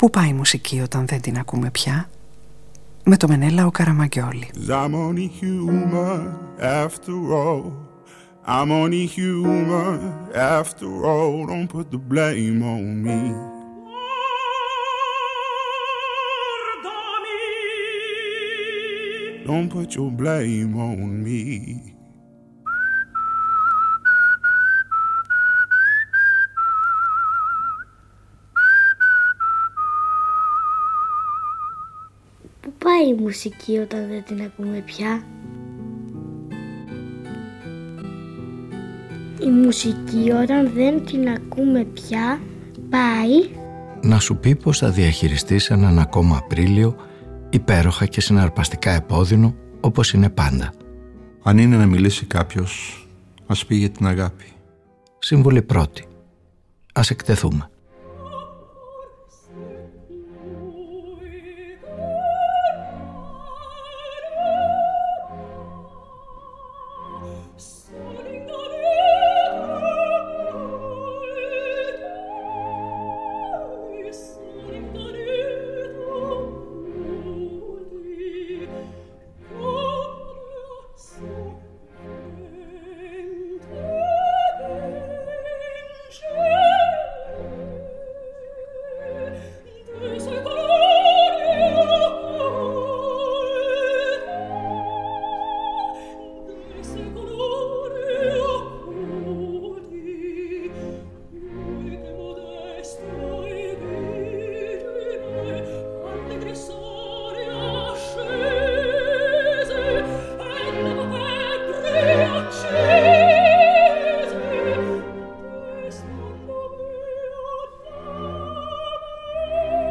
24 οδηγίες-συμβουλές-παραινέσεις συνδράμουν τους ήρωες μιας ραδιοφωνικής ταινίας να τα βγάλουν πέρα με έναν δύσκολο μεταβατικό Απρίλιο μέσα από κουβέντες, σαματάδες, διαφωνίες, καυγάδες, φλερτ, αγκαλιές, κλάματα, προπόσεις, ξανά αγκαλιές κι αποχαιρετισμούς που μοιάζουν με προσκλήσεις και διευκολύνουν τις επιστροφές. Ανάμεσα σε γύρη, μυρωδιές, και υποσχετικά ξεπετάγματα της βλάστησης, οι θεοί πεθαίνουν, σίγουροι πως θα ξαναγεννηθούν.